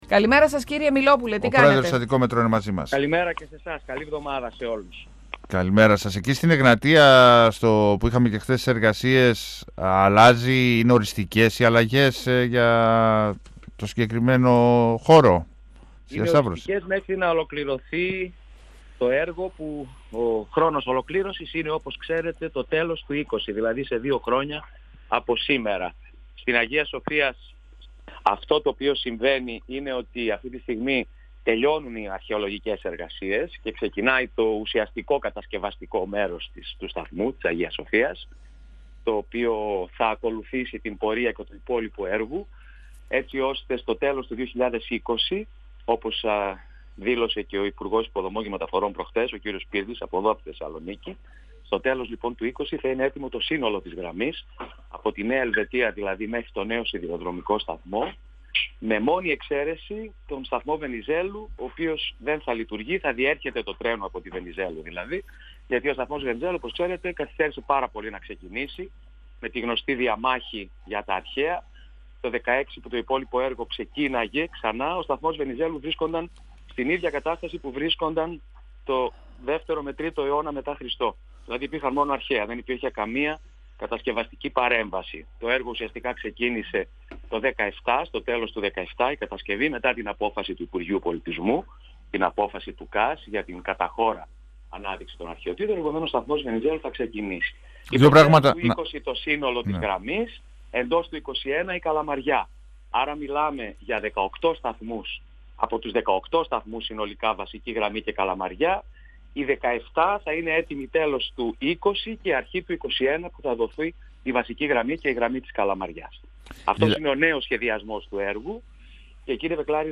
Ο πρόεδρος της Αττικό Μετρό Γιάννης Μυλόπουλος, στον 102FM του Ρ.Σ.Μ. της ΕΡΤ3